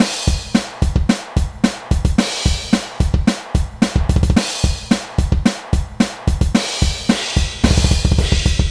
波特诺依打击乐鼓乐曲改进版
描述：循环由一个简单的打击乐模式组成，一个类似于Mike Portnoy倾向于使用的模式。
Tag: 110 bpm Heavy Metal Loops Percussion Loops 1.47 MB wav Key : Unknown